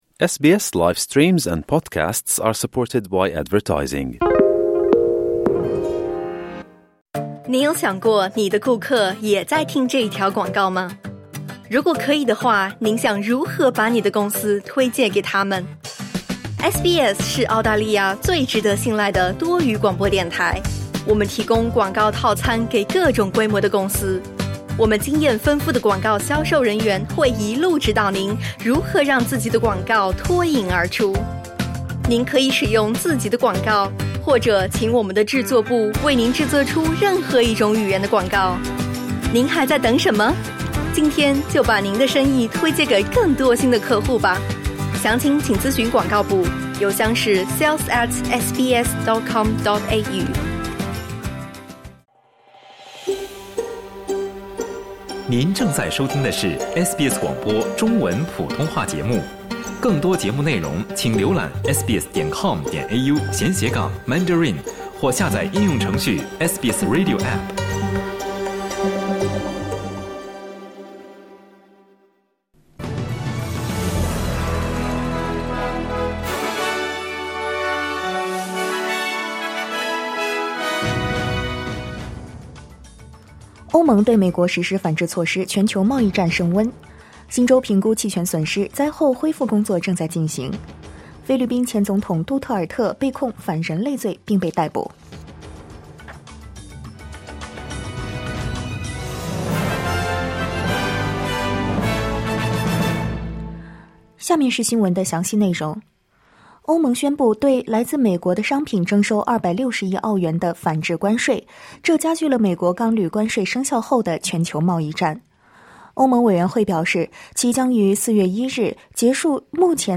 SBS早新闻（2025年3月13日）
SBS Mandarin morning news.